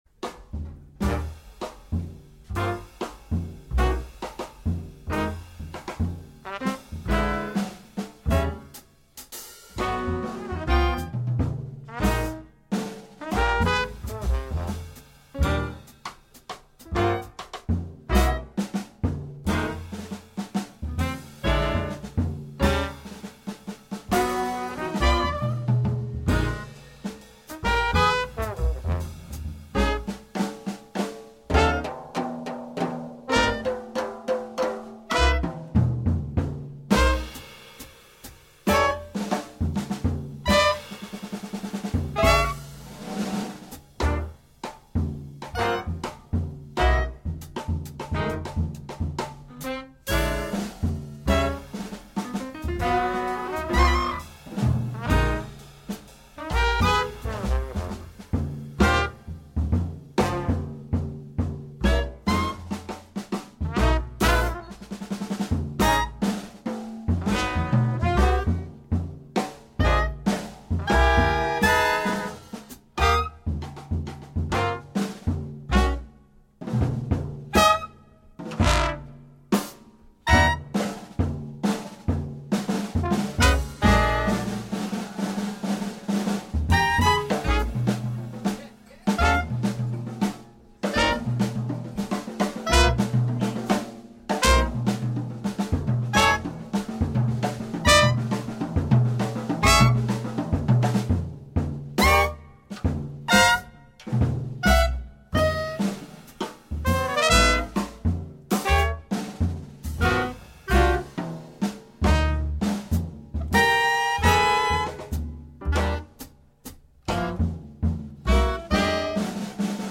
Download the Transcription: Drums